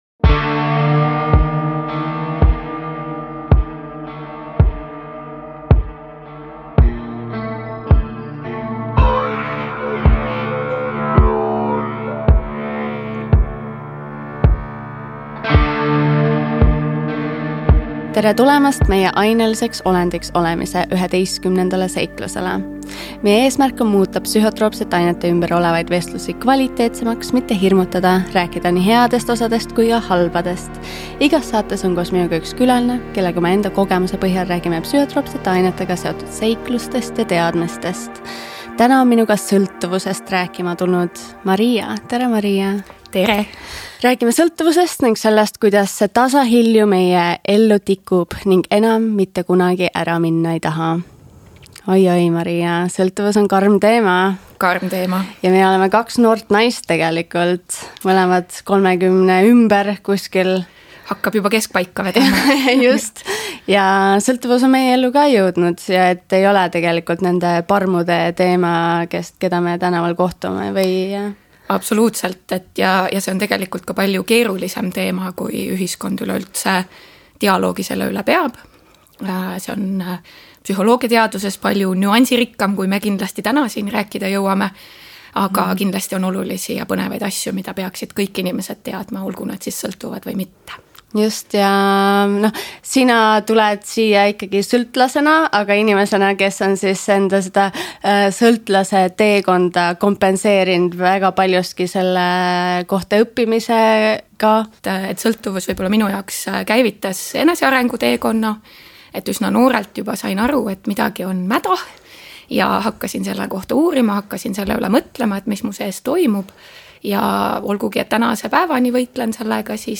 Igas saates on koos saatejuhiga üks külaline, kellega tulevad jutuks nii teadmised, müüdid kui ka inimeste isiklikud kogemused